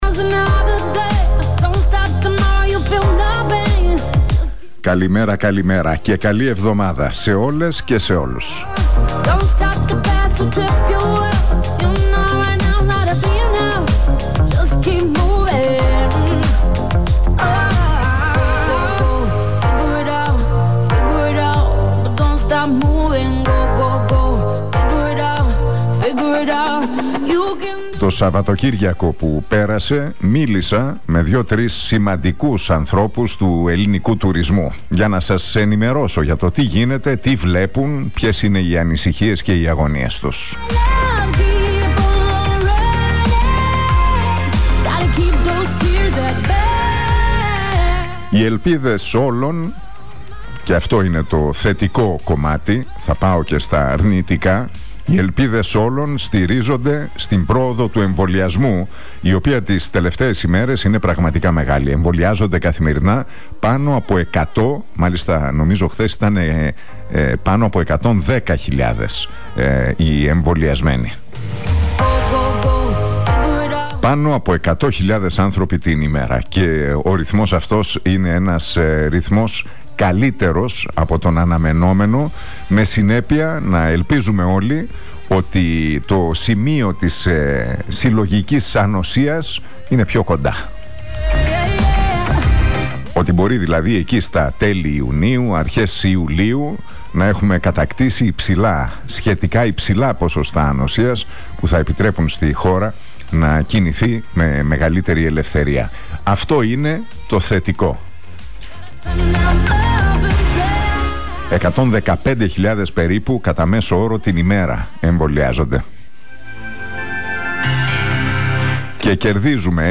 Ακούστε το σημερινό σχόλιο του Νίκου Χατζηνικολάου στον Realfm 97,8...